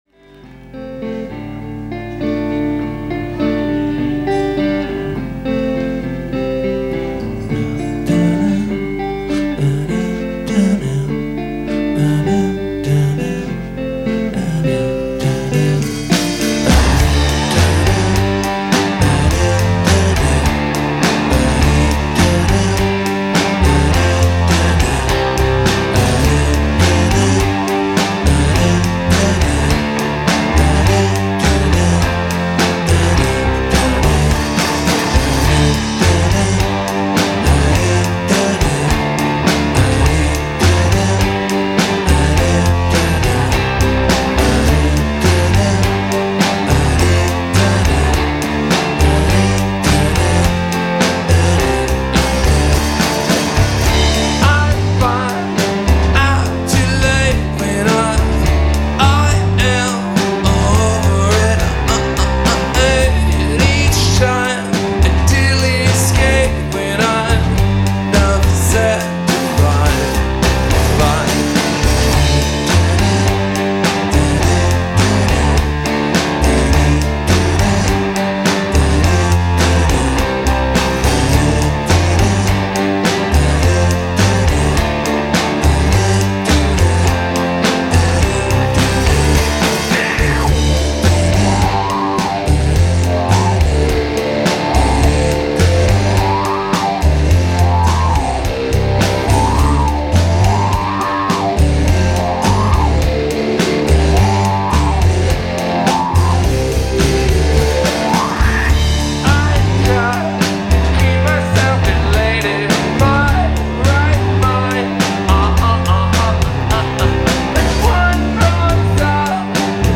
Neo-Psychedelia, alternative rock, garage Rock and Synthpop